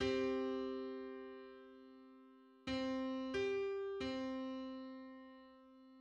File:Three-hundred-eighty-fifth harmonic on C.mid - Wikimedia Commons
Public domain Public domain false false This media depicts a musical interval outside of a specific musical context.
Three-hundred-eighty-fifth_harmonic_on_C.mid.mp3